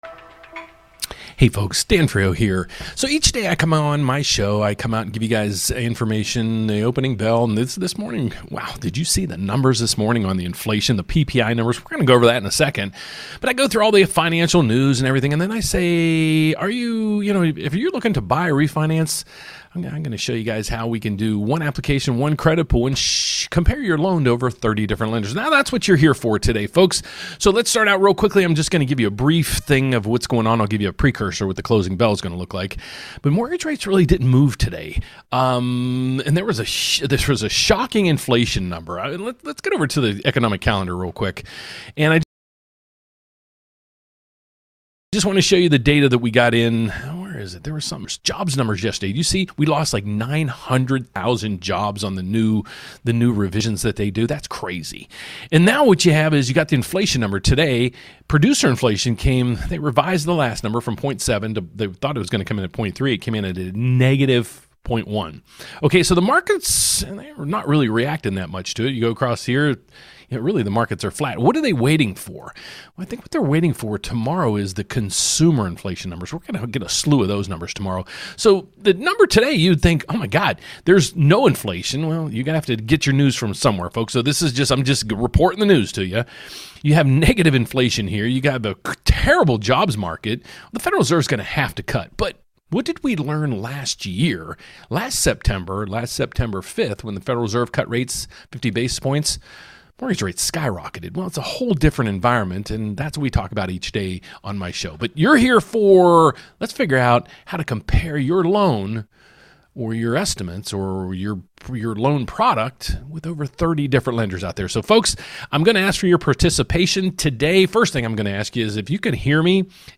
Tired of filling out multiple applications and taking multiple credit hits just to shop for the best mortgage rate? In this live event, I’ll show you how to: